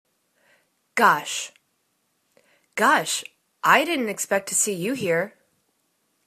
gosh    /gosh/    interjection